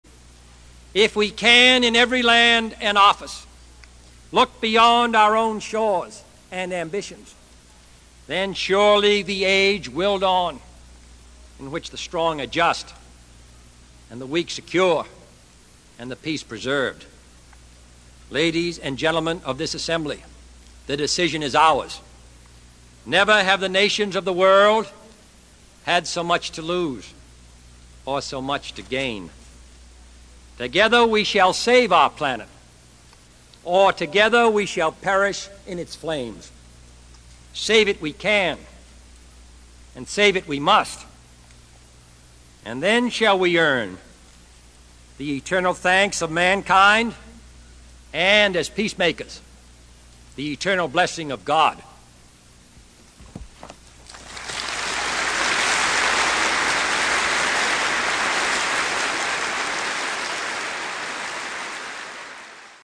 John F Kennedy Address to United Nations 11
Tags: John F. Kennedy John F. Kennedy Address United Nations John F. Kennedy speech President